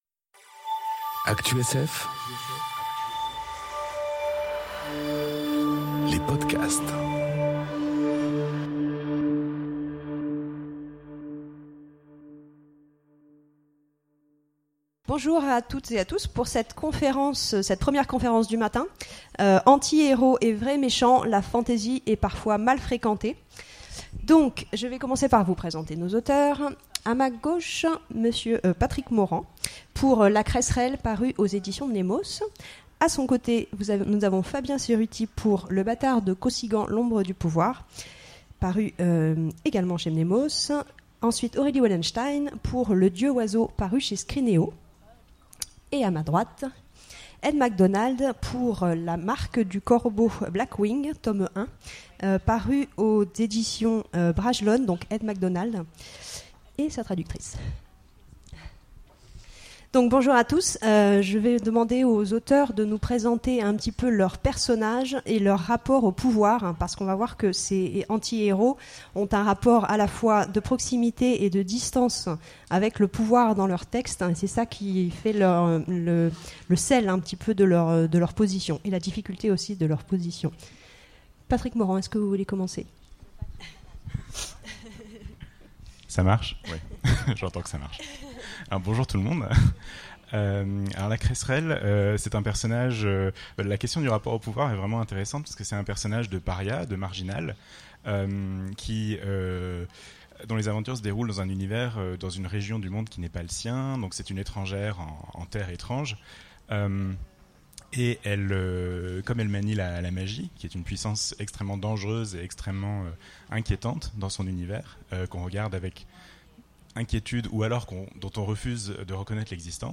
Conférence Anti-héros et vrais méchants : la fantasy est parfois mal fréquentée enregistrée aux Imaginales 2018